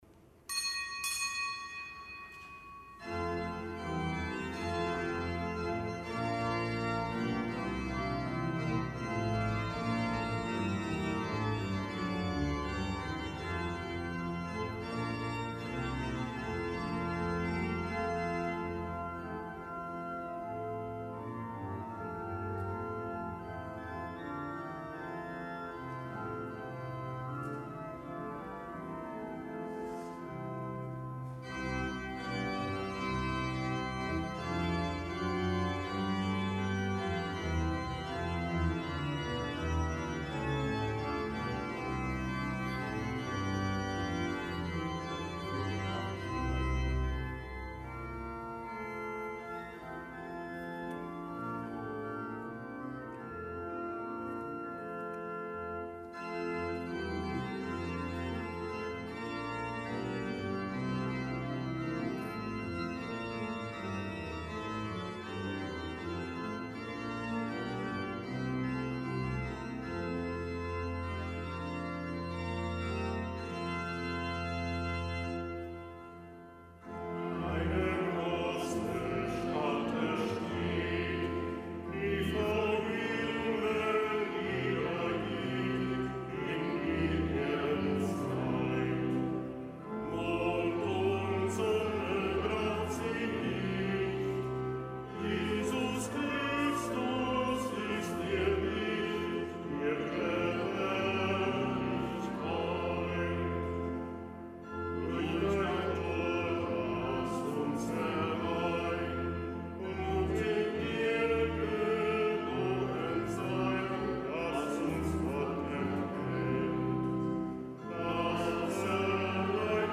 Kapitelsmesse am Fest Weihetag der Lateranbasilika